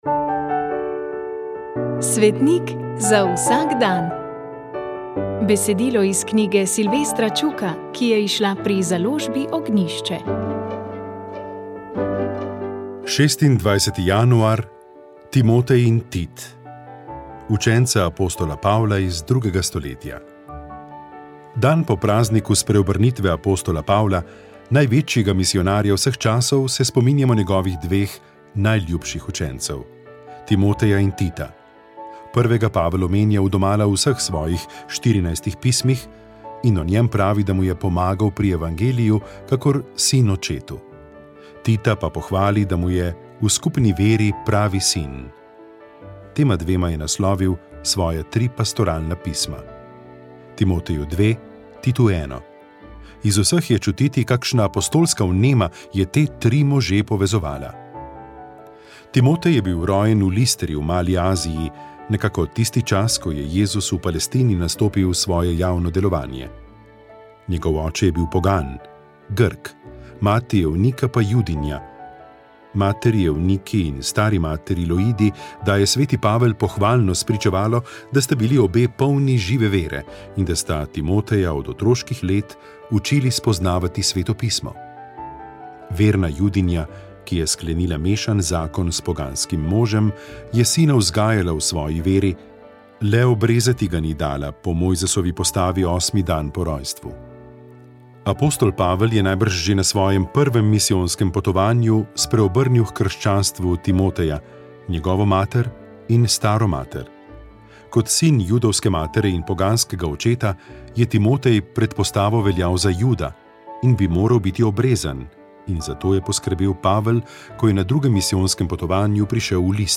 Duhovni nagovor
Nagovor in razmišljanje ob evangelijskih odlomkih preteklih dni je pripravil ljubljanski nadškof Stanislav Zore.